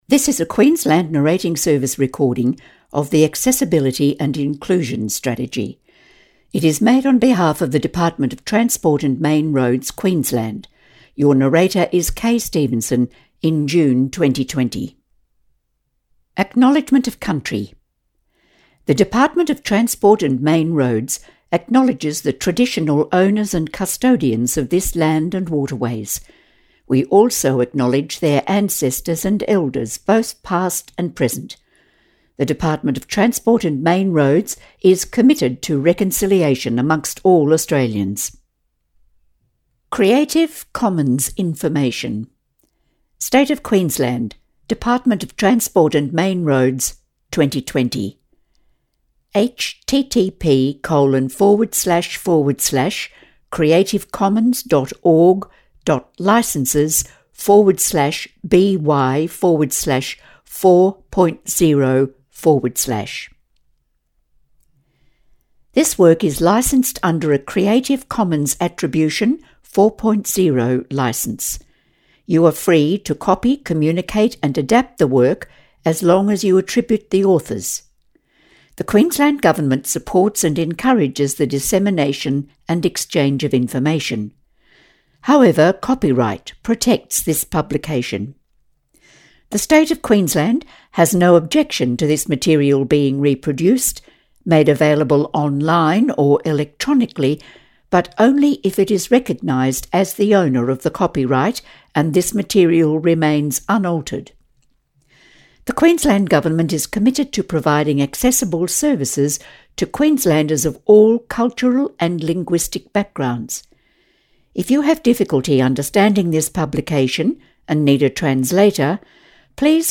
Narrated version